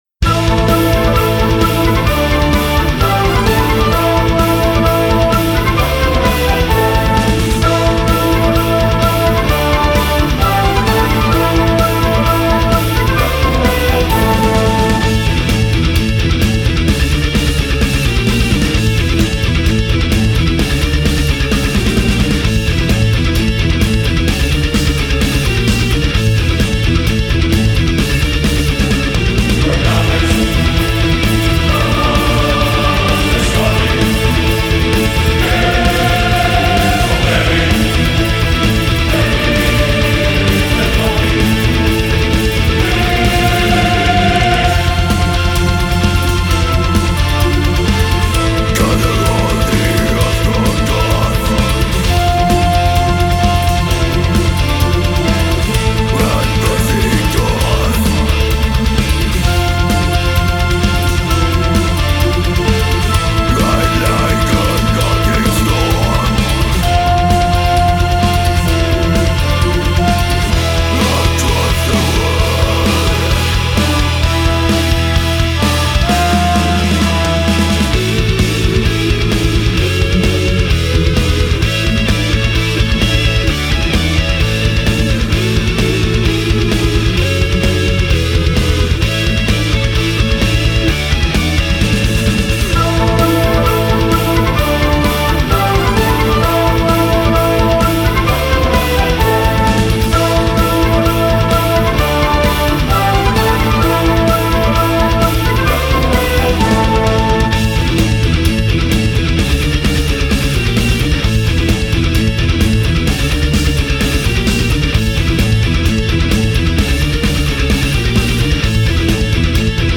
Музыкальный хостинг: /Металл